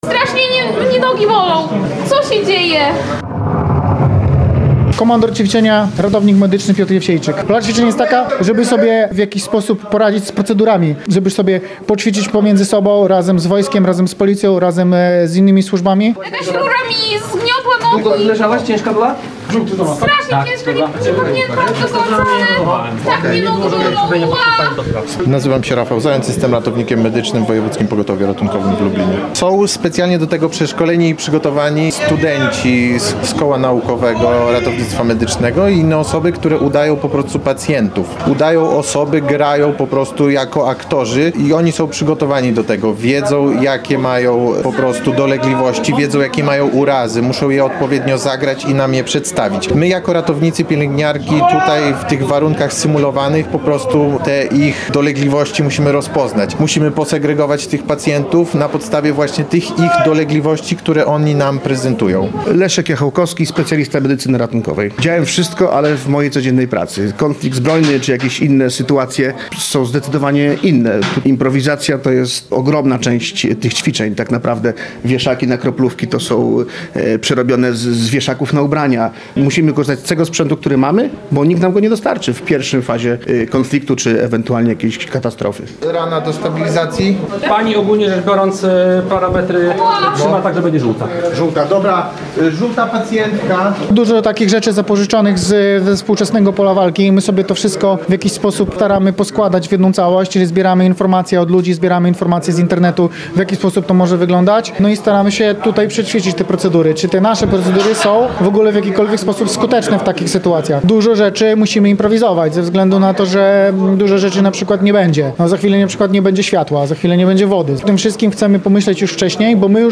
Nowoczesny sprzęt na lubelskiej uczelni [ZDJĘCIA] Szkoleniom przyglądała się nasza reporterka.